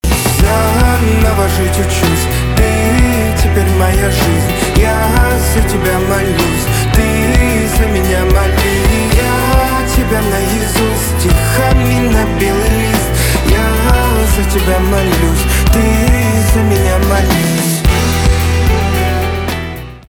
русский рок , гитара , барабаны
романтические , чувственные